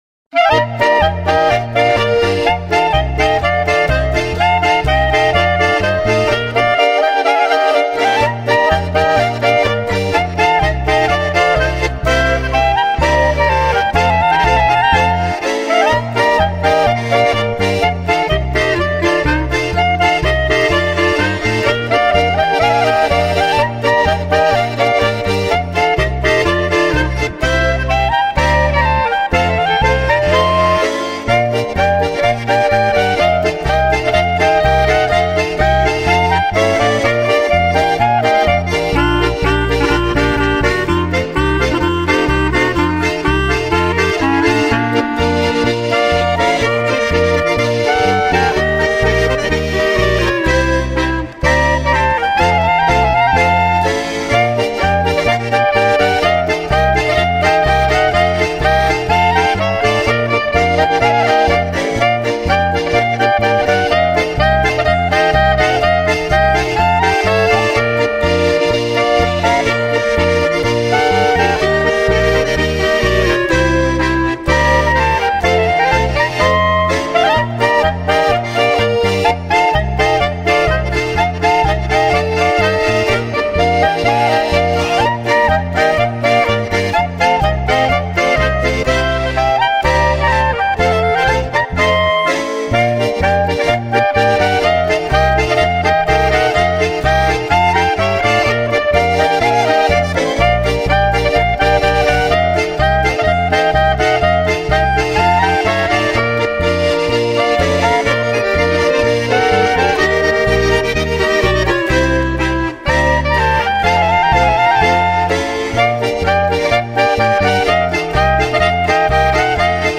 Instrumental music.